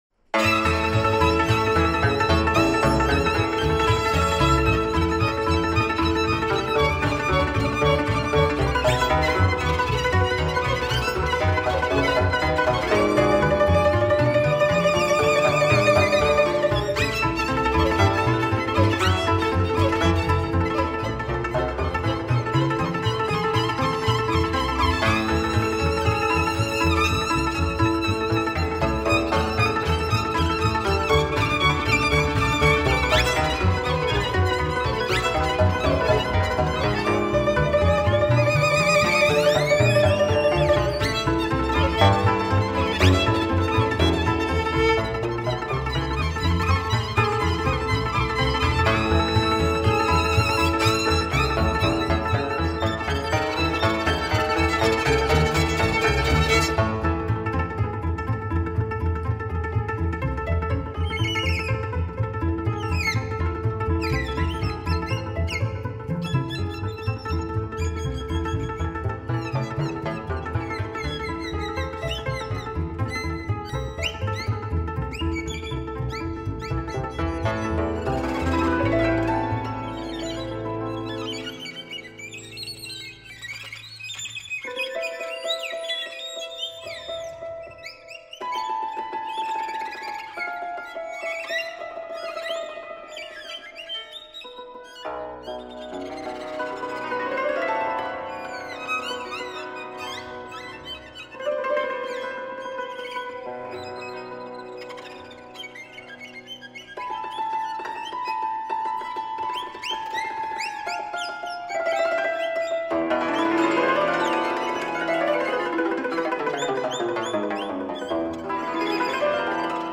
Romanian folk music.